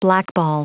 Prononciation du mot blackball en anglais (fichier audio)
Prononciation du mot : blackball